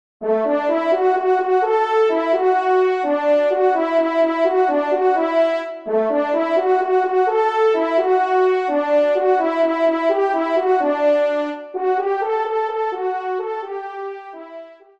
1ère Trompe